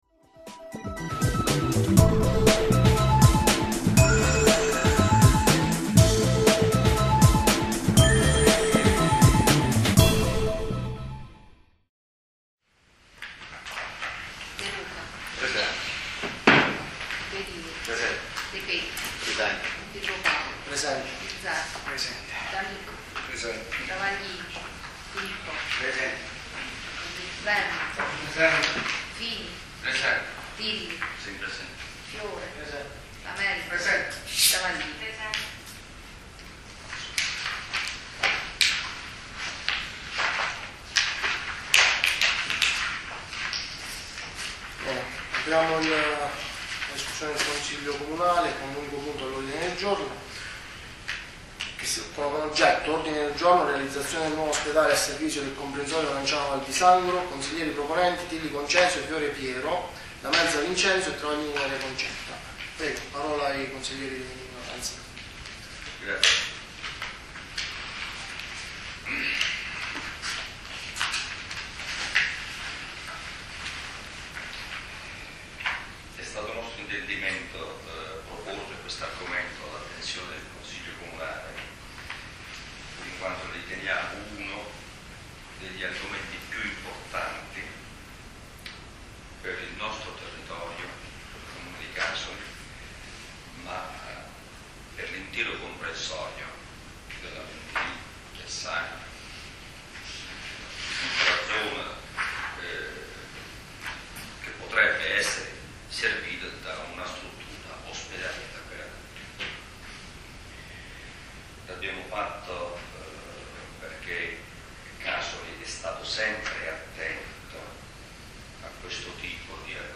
Ascolta il Consiglio Comunale del 7 Novembre 2012